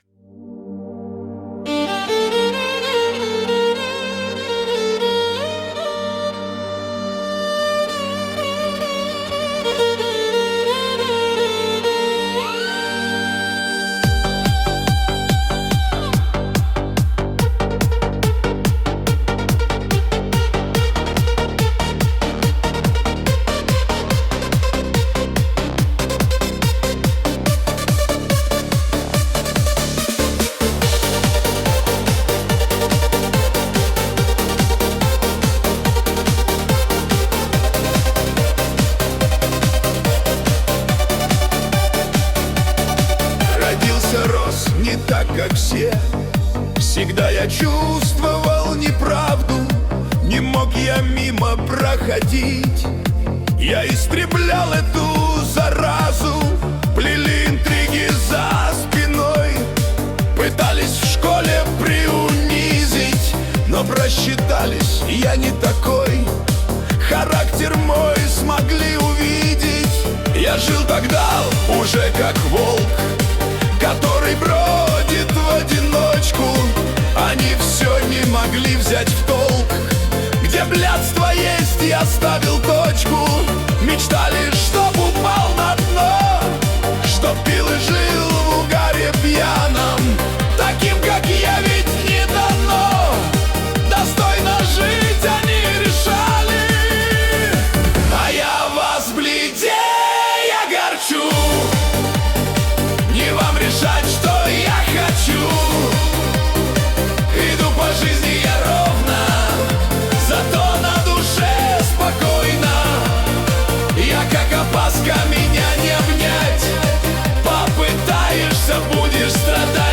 Качество: 320 kbps, stereo
Стихи, Нейросеть Песни 2025